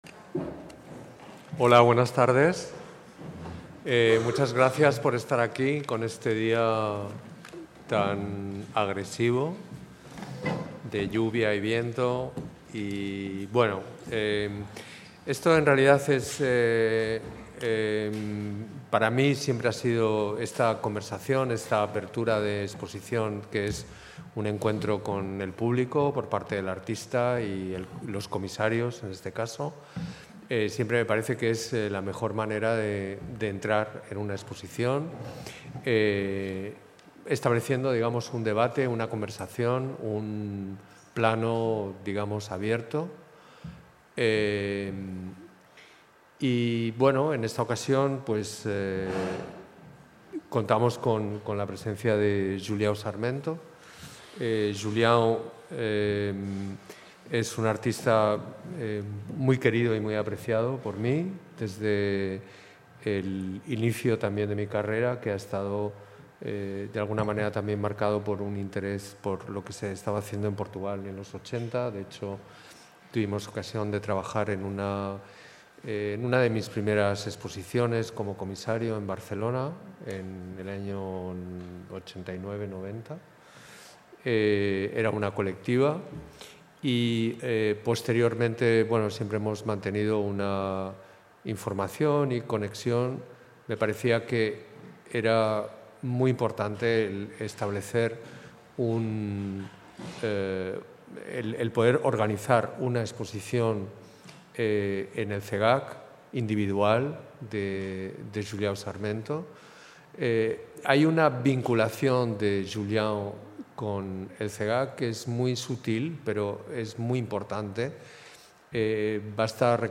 Sarmento. Encontro co público.mp3